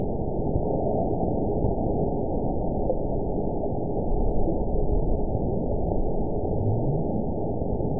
event 920633 date 04/01/24 time 15:58:41 GMT (1 year, 1 month ago) score 9.67 location TSS-AB01 detected by nrw target species NRW annotations +NRW Spectrogram: Frequency (kHz) vs. Time (s) audio not available .wav